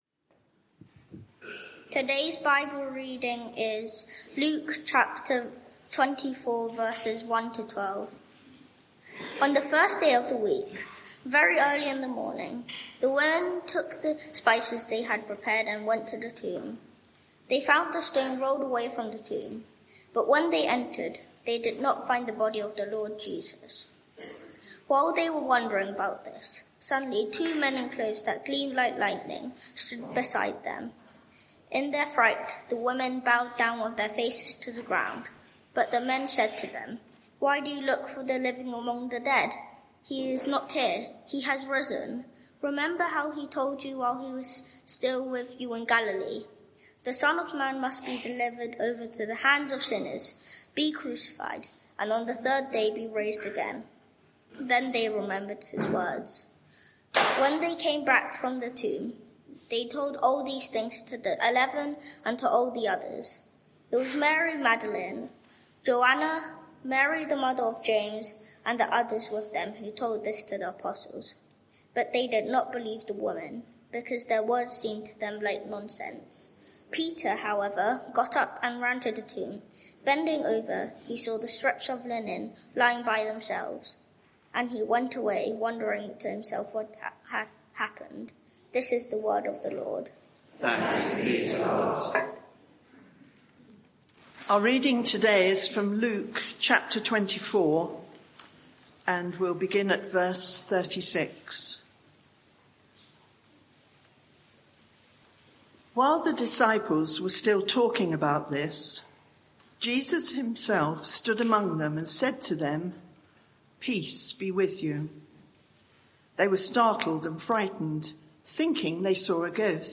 Media for 11am Service on Sun 20th Apr 2025 11:00 Speaker
Sermon (audio) Search the media library There are recordings here going back several years.